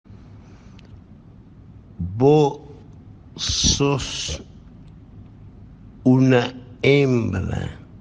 vo sos una hembra Meme Sound Effect